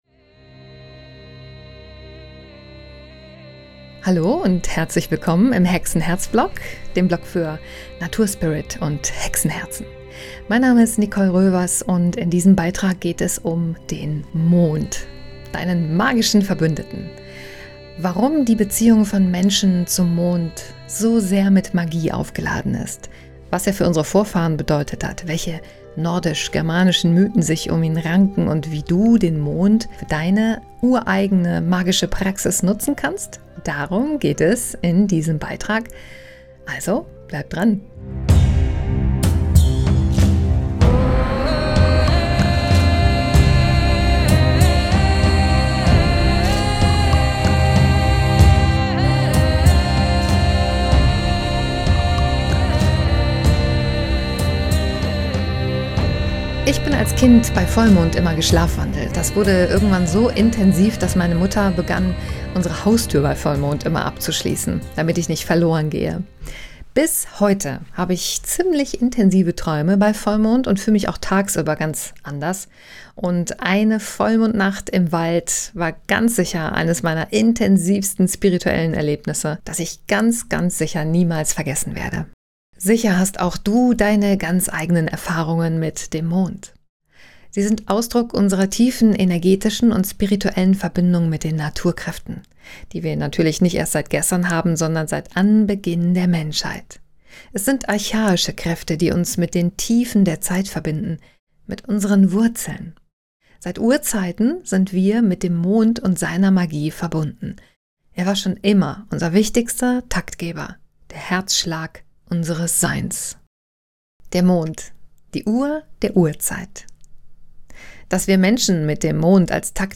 Du kannst diesen Blogbeitrag unten weiterlesen oder dir von mir erzählen lassen, denn ich habe ihn für dich eingesprochen, dafür einfach hier unten auf den Pfeil klicken: Der Mond